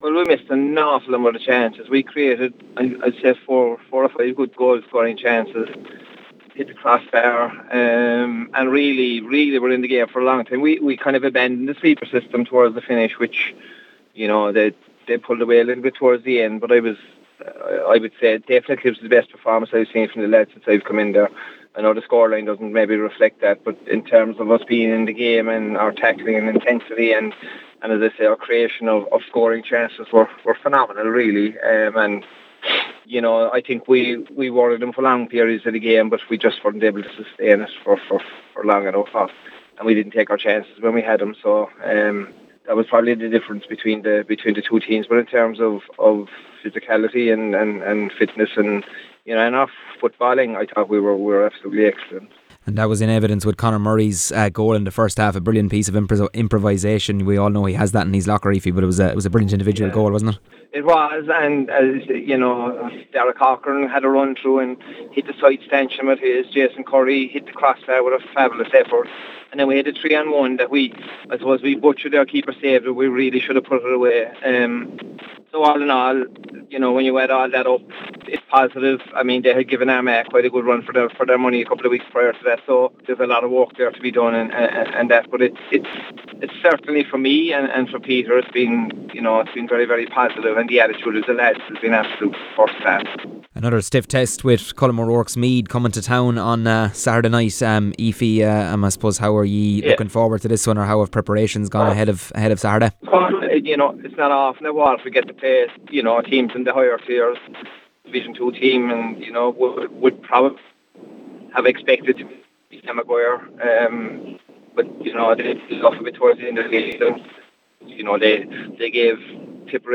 speaking on last night’s “ Lár Na Páirce” programme